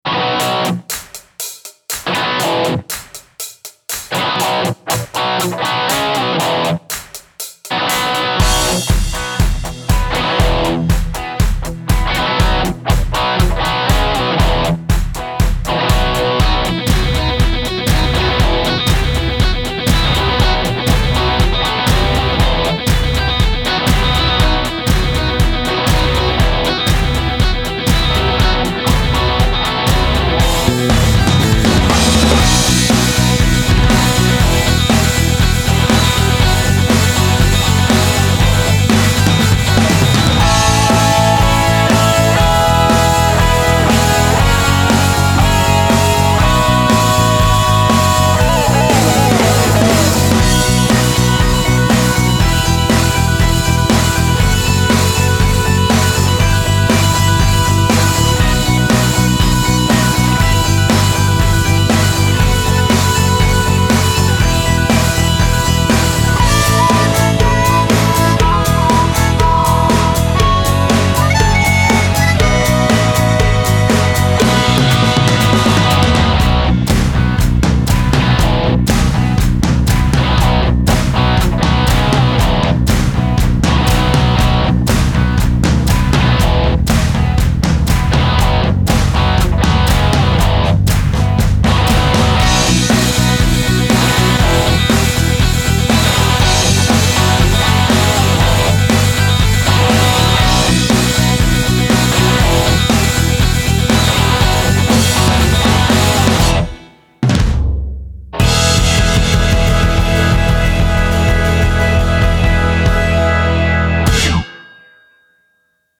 Хард-Рок.